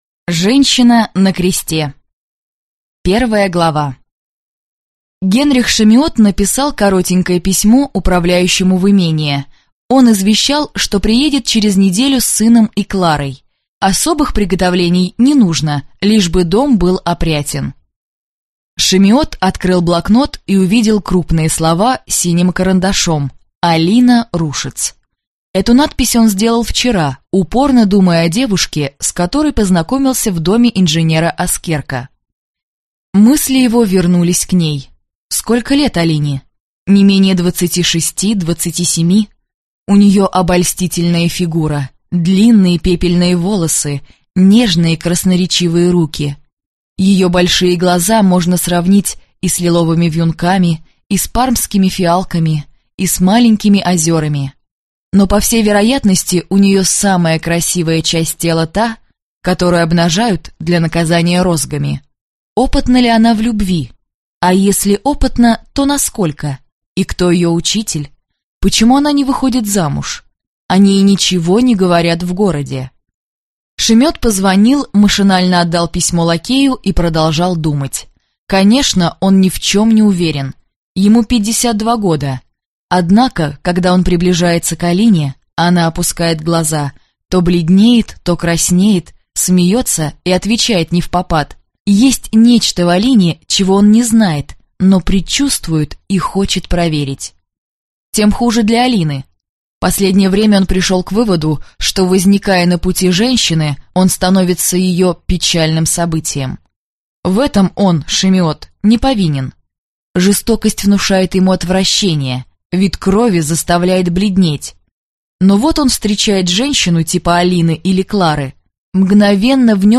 Аудиокнига Женщина на кресте | Библиотека аудиокниг